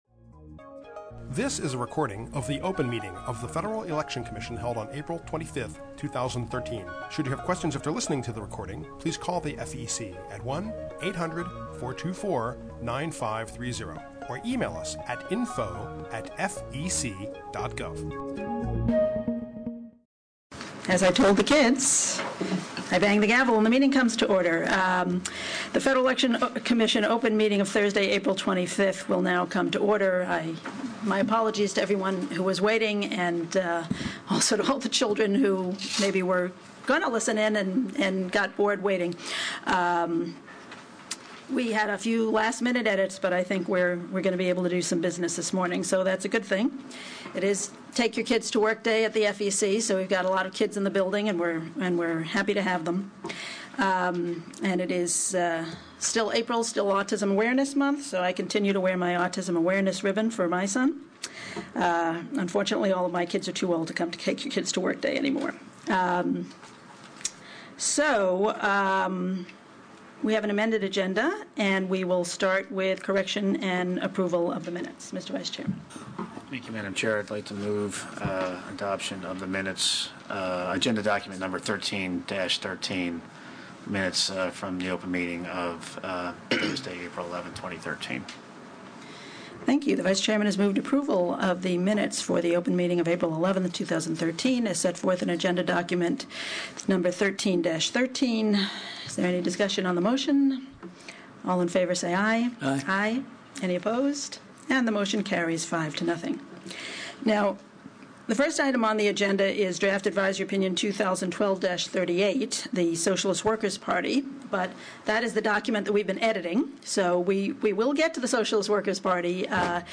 April 25, 2013 open meeting